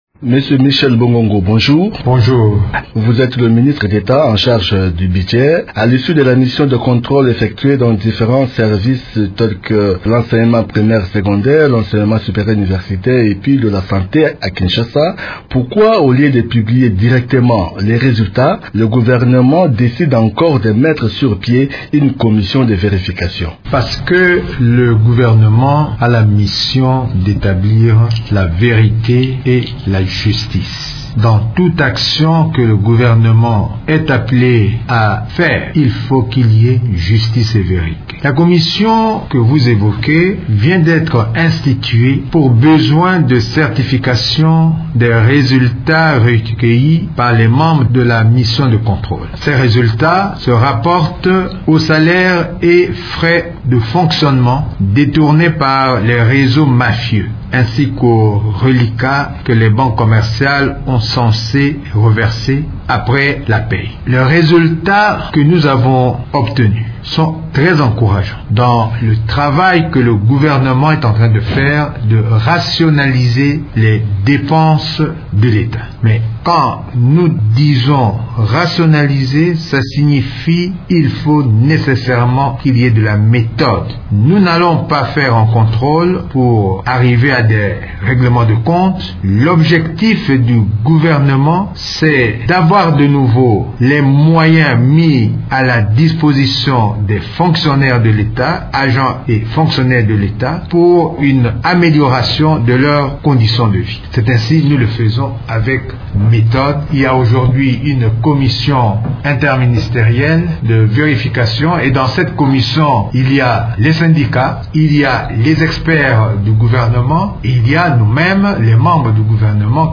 Invité de Radio Okapi ce matin, le ministre d'Etat chargé du Budget, Michel Bongongo Ikoli a indiqué qu’à travers cette commission, le gouvernement entend rationnaliser les dépenses de l'Etat.